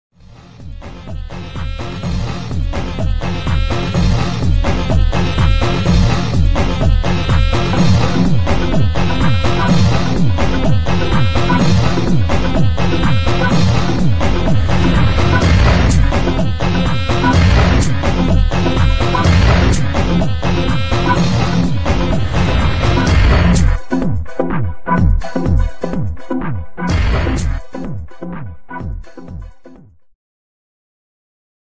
Segment Jazz-Rock
Progressive